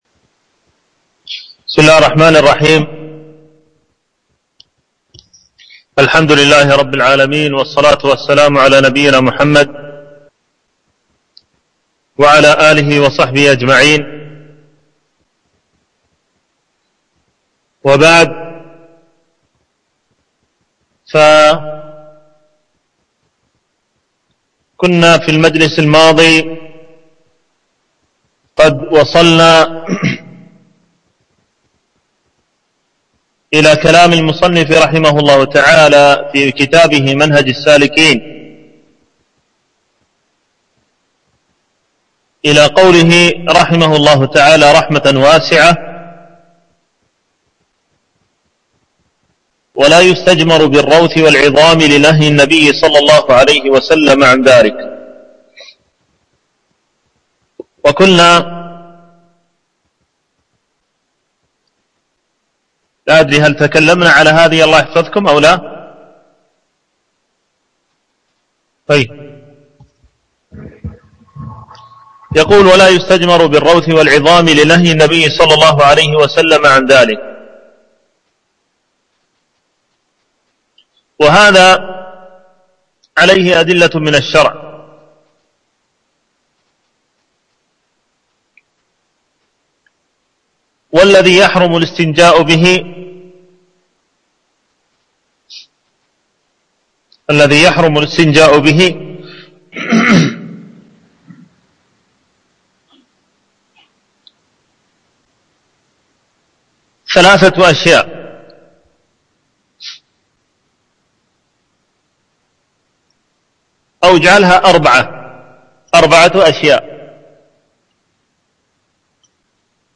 العنوان: الدرس السادس
التنسيق: MP3 Mono 22kHz 40Kbps (CBR)